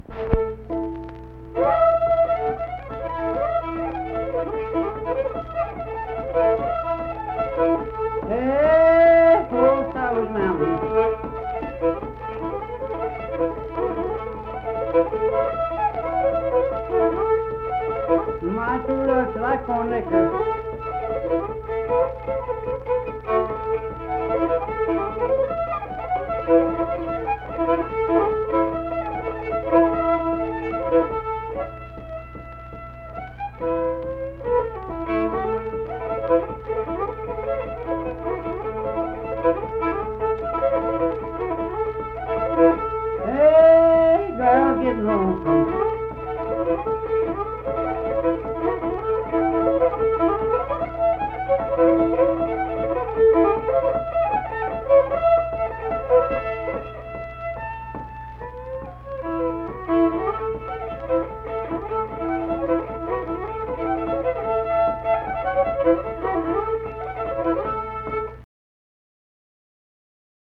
Unaccompanied vocal and fiddle music
Instrumental Music
Fiddle, Voice (sung)
Mingo County (W. Va.), Kirk (W. Va.)